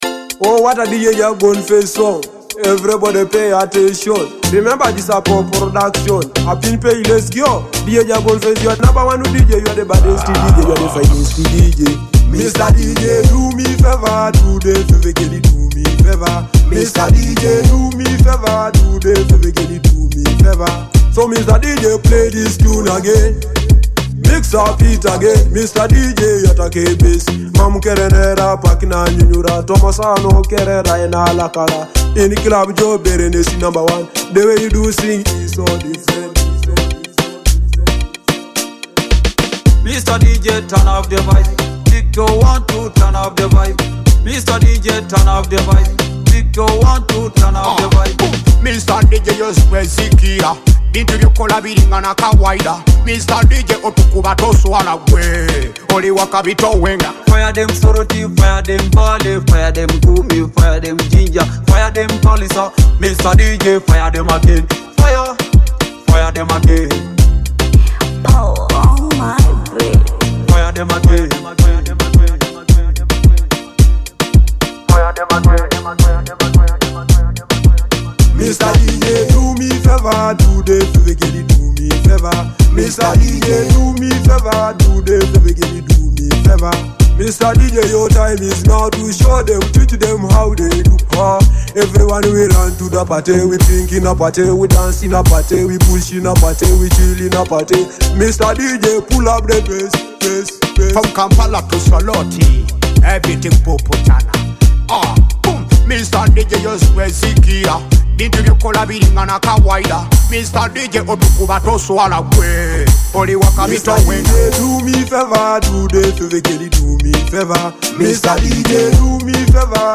a high-energy, club-ready track available now in MP3 format.
pulsating beats